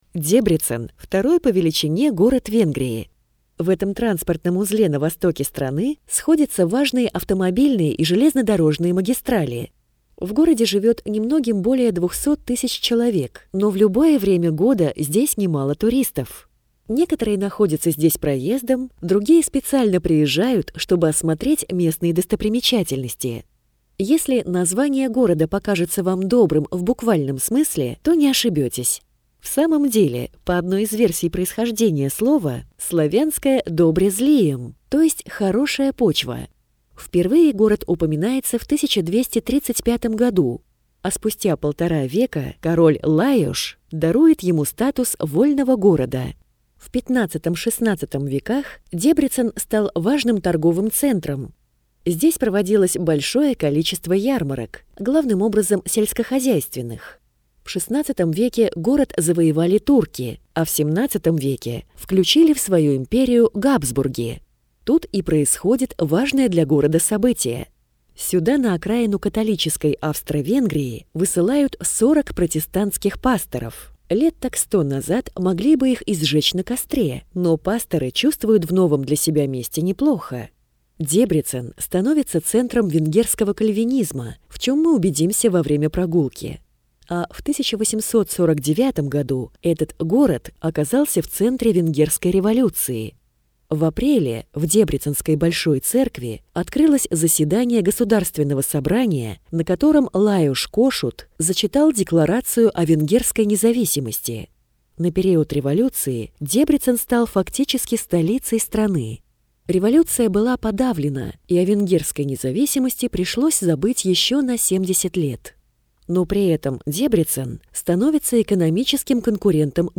Аудиокнига Дебрецен. Аудиогид | Библиотека аудиокниг